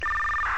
Mdc_preamble.mp3